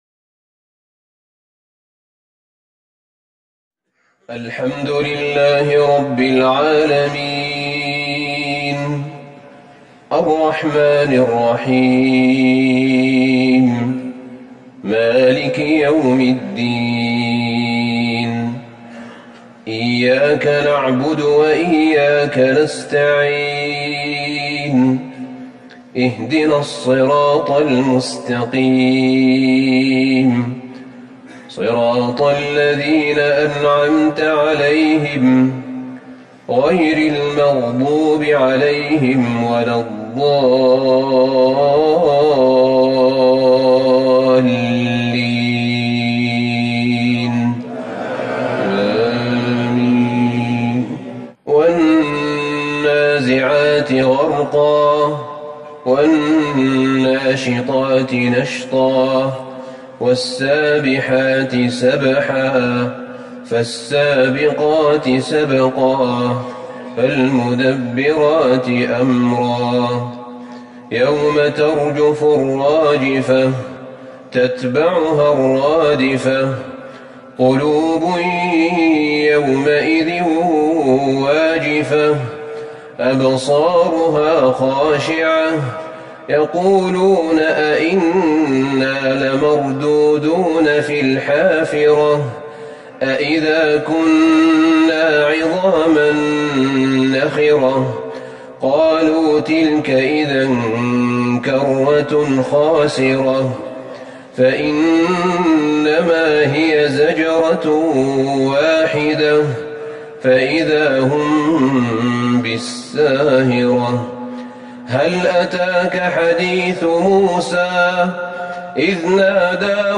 صلاة العشاء ٢١ جمادي الاولى ١٤٤١هـ سورة النازعات Evening prayer 9-1-2020 from Surah An-Naza'at > 1441 🕌 > الفروض - تلاوات الحرمين